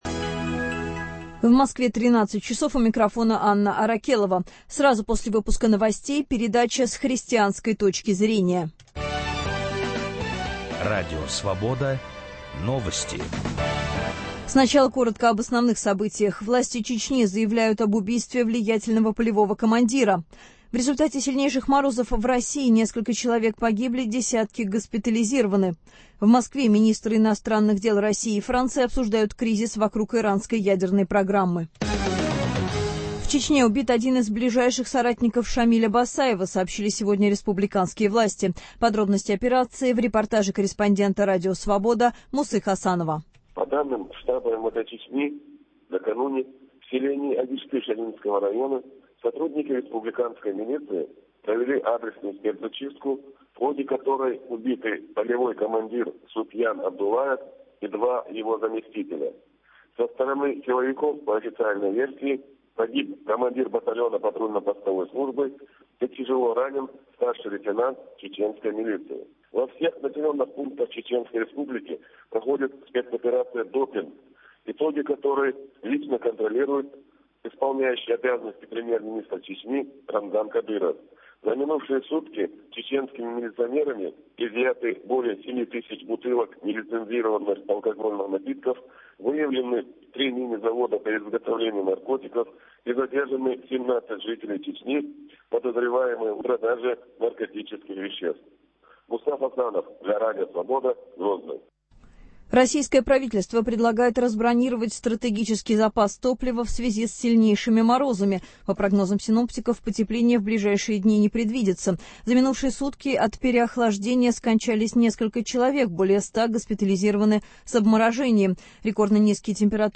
Утром в газете, а с часу дня в прямом эфире - обсуждение самых заметных публикации российской и зарубежной печати. Их авторы и герои - вместе со слушателями.